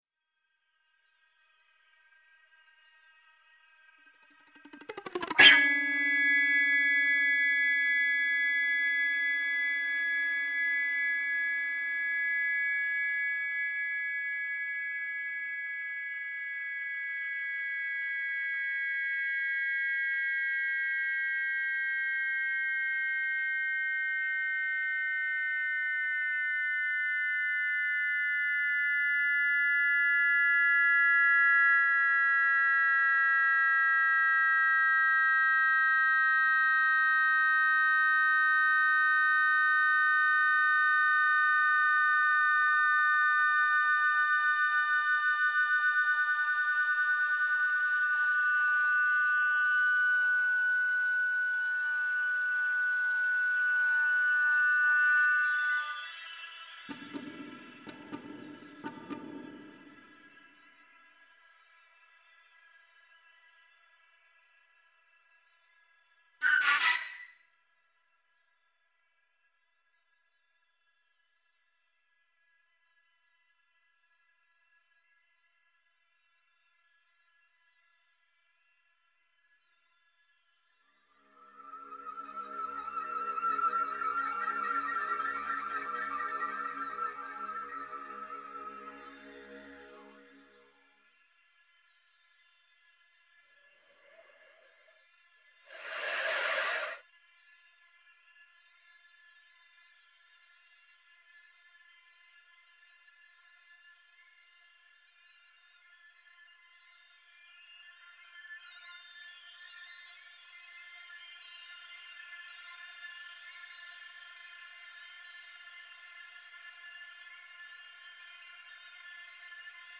für Flöte, Klavier und 6-Kanal-Zuspielung
Ausschnitte Mono